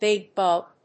アクセントbíg búg